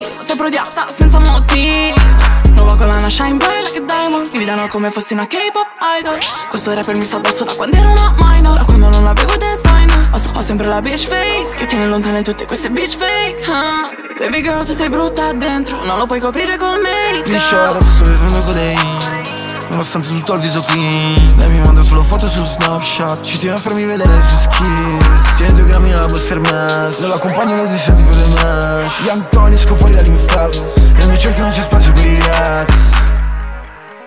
Categoria Hip Hop